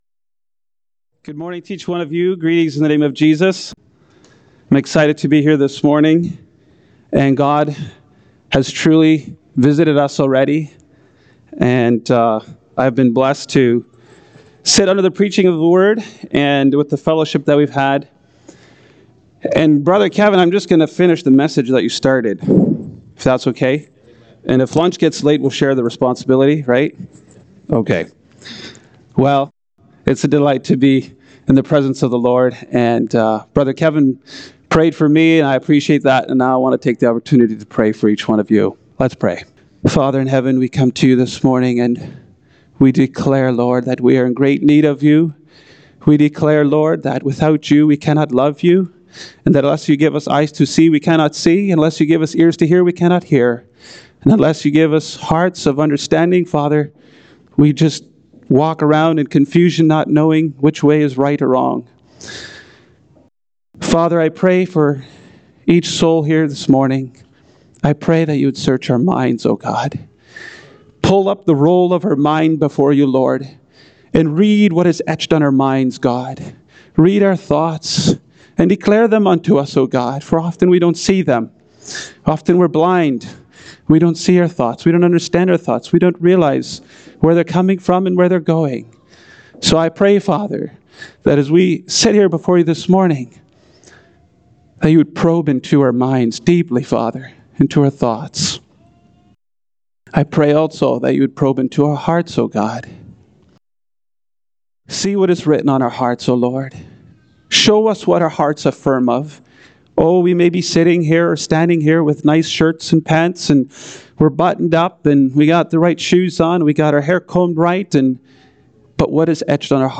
Sunday Morning Message
Service Type: Fellowship Weekend, Sunday Sermons